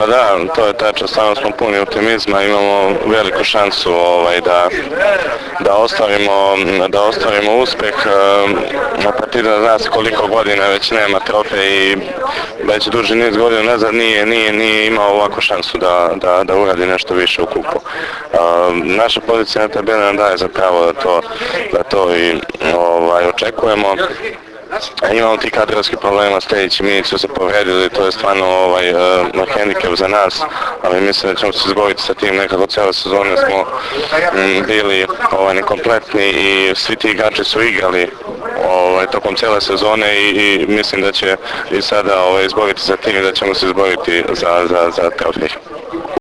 U pres sali SD Crvena zvezda danas je održana konferencija za novinare povodom Finalnog turnira 46. Kupa Srbije u konkurenciji odbojkaša.
IZJAVA